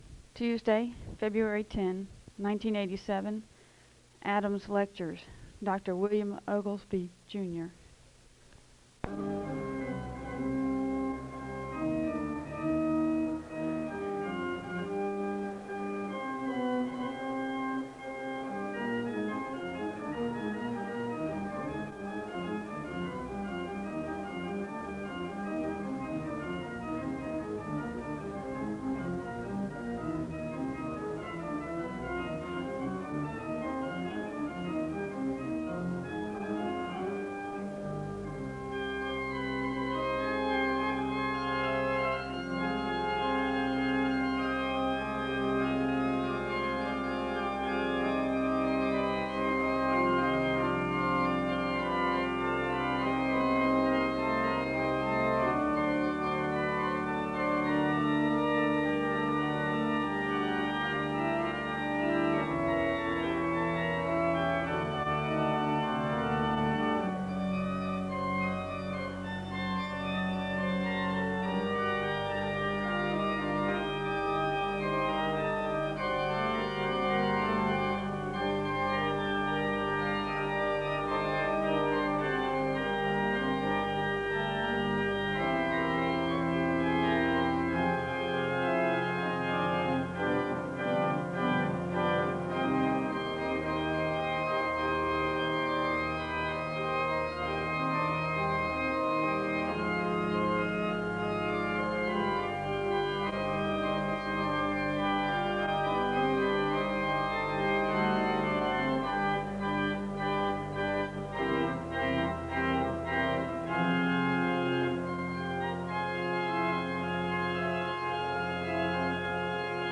The service begins with organ music (0:00:00-0:06:12). There is a Scripture reading from Mark 3 and a moment of prayer (0:06:13-0:8:32). A welcome is extended to the guests of the Adams Lectures (0:8:33-0:10:57).
The choir sings an anthem (0:15:43-0:18:30).
The service concludes with a prayer (1:00:41-1:00:55).